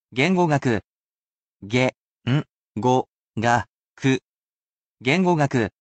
However, I do not only give you useful definitions, romanisation, hiragana, and kanji, but I also give the pronunciation for you to listen to as many times as you wish.
As a bilingual computer, I can relate to this topic.